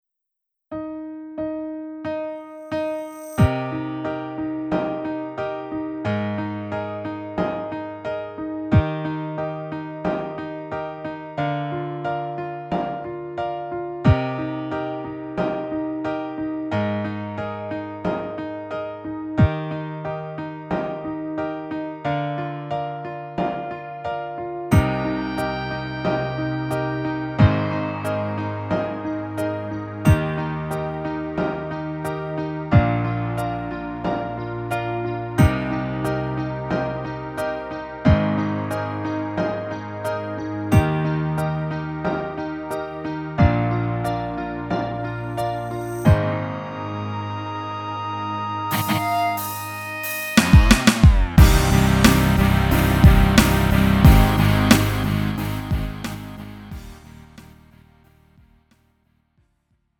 음정 -1키 3:30
장르 가요 구분 Lite MR